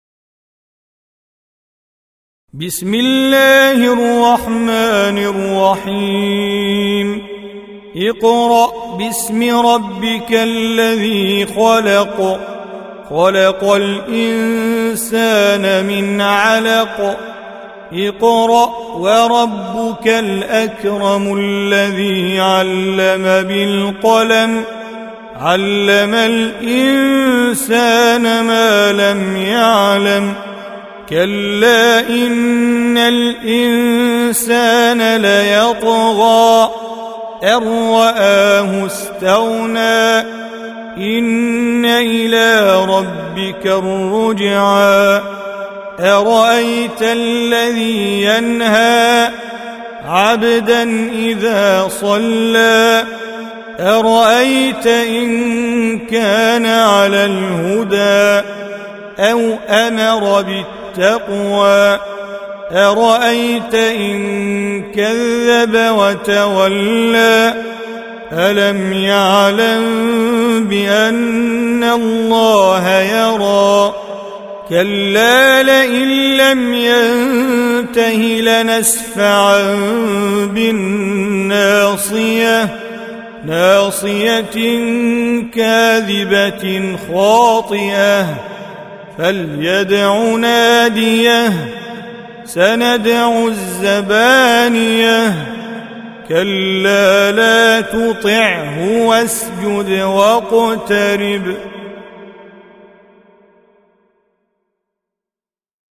Surah Repeating تكرار السورة Download Surah حمّل السورة Reciting Mujawwadah Audio for 96. Surah Al-'Alaq سورة العلق N.B *Surah Includes Al-Basmalah Reciters Sequents تتابع التلاوات Reciters Repeats تكرار التلاوات